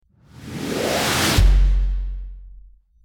Time Travel Whoosh 04
Time_travel_whoosh_04.mp3